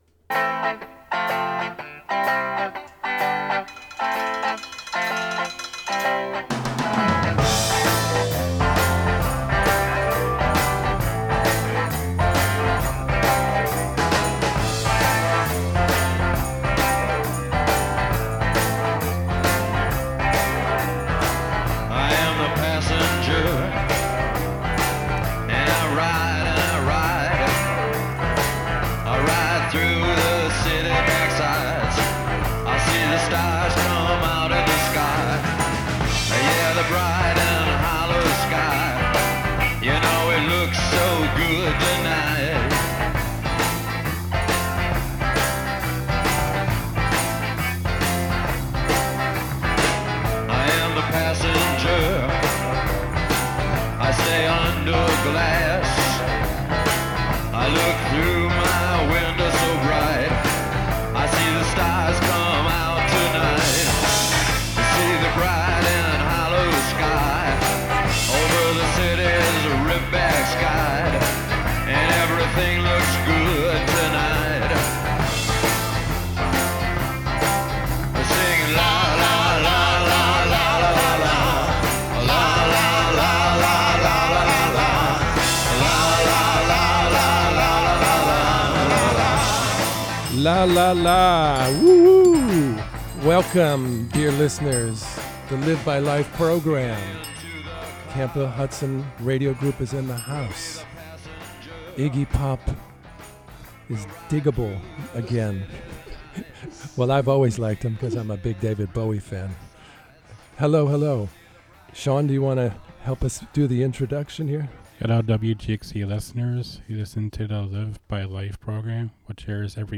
Members of the Camphill Hudson Radio Group from Camphill Hudson make their own radio.